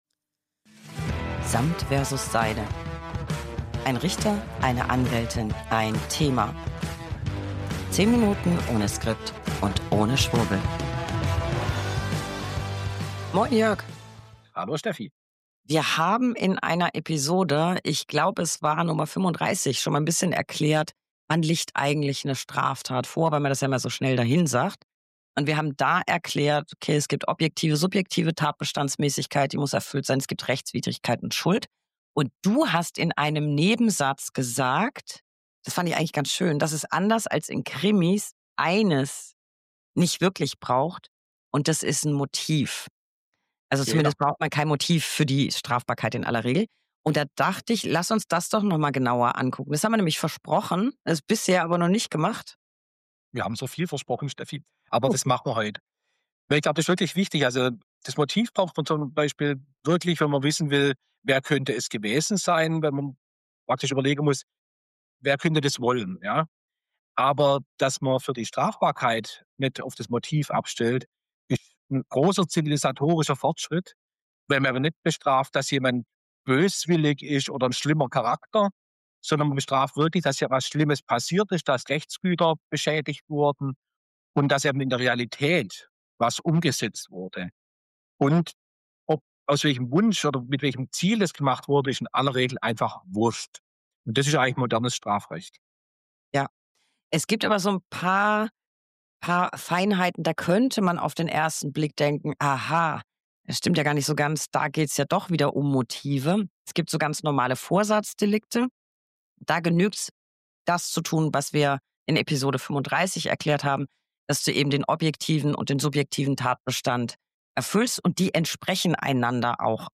1 Anwältin + 1 Richter + 1 Thema. 10 Minuten ohne Skript und ohne Schwurbel. Ob jemand ein Tatmotiv hatte oder nicht, ist vor allem für die Ermittlungen interessant. Bei der Strafbarkeit einer Tat interessiert das eigentlich nicht.